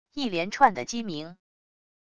一连串的鸡鸣wav音频